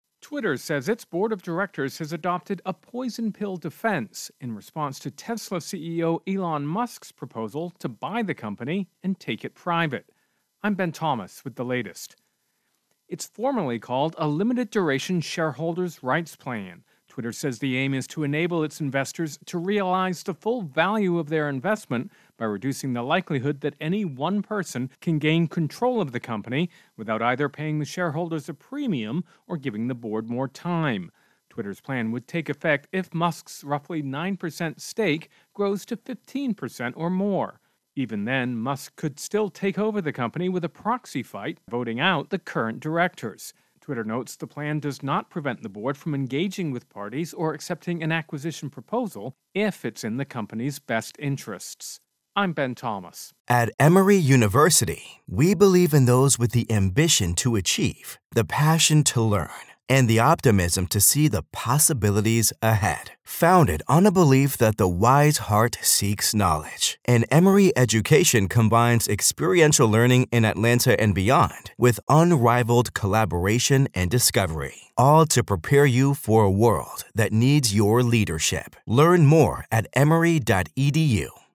Intro and voicer "Twitter Musk"